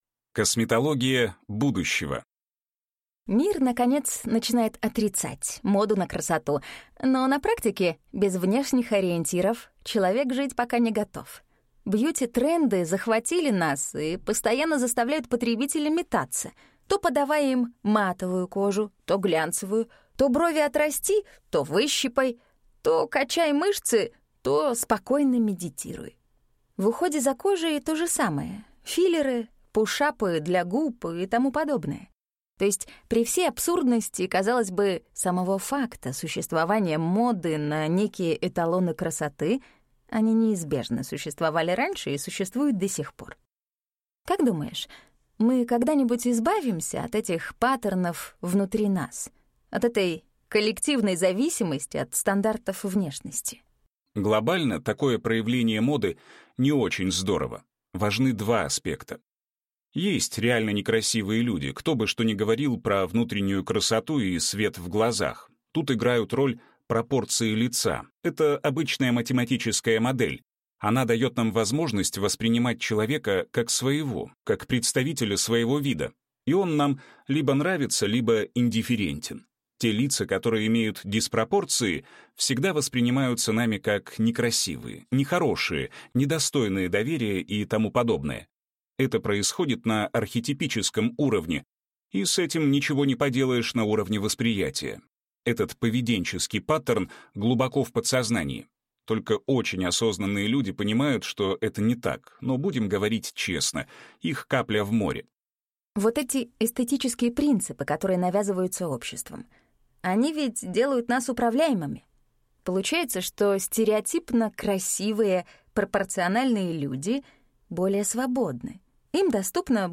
Аудиокнига Косметология будущего | Библиотека аудиокниг